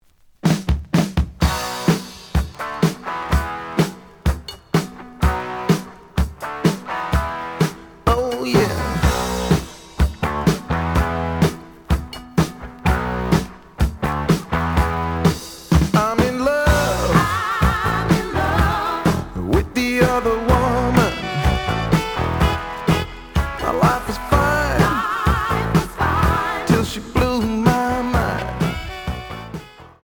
The audio sample is recorded from the actual item.
●Genre: Funk, 80's / 90's Funk
Looks good, but slight noise on both sides.)